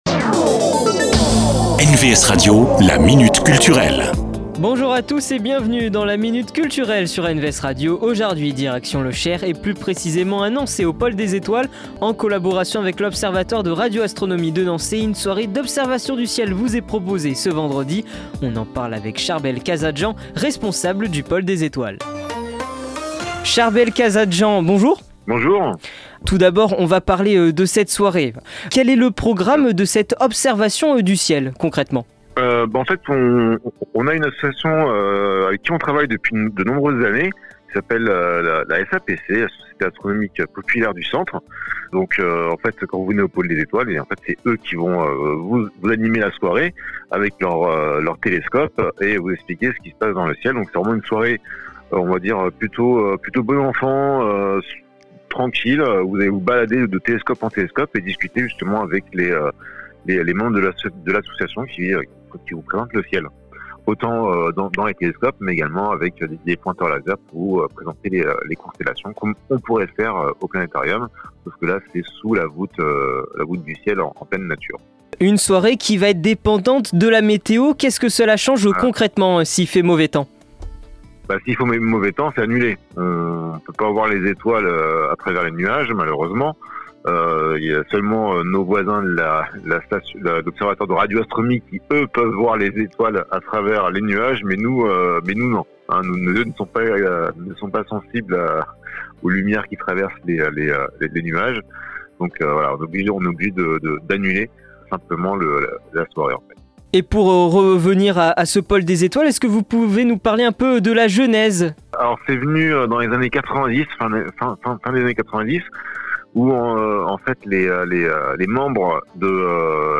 La Minute Culture, rencontre avec les acteurs culturels de votre territoire.